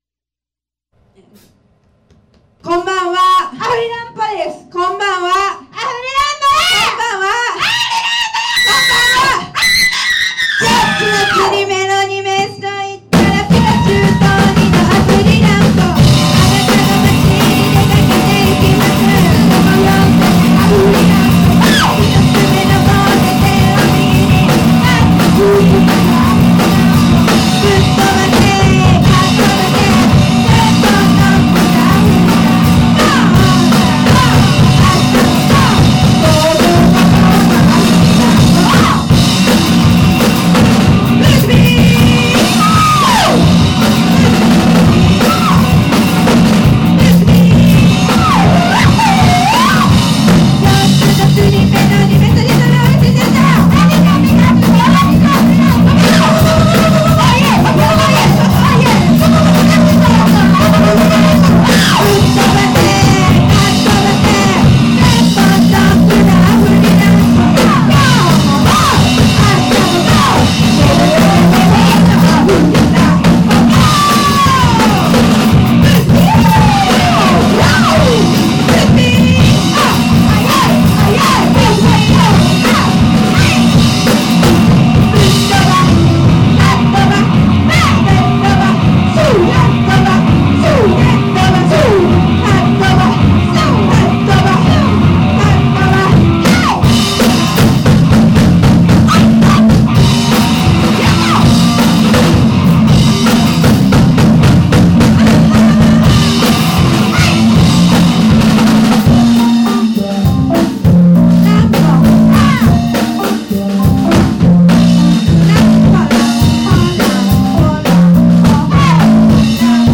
powerful, primitive stomp-and-roll
A guitar and drum duo
deciding that drums, guitar, and vocals would suffice.